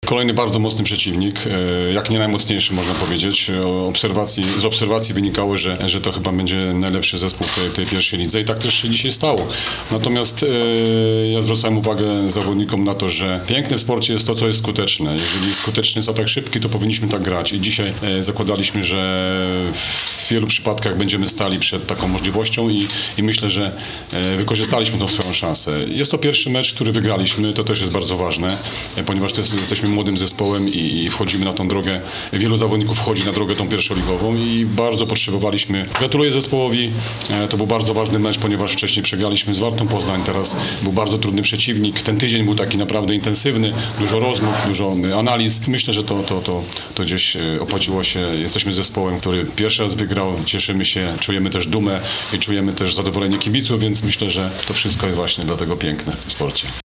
chwalił na konferencji prasowej zespół gości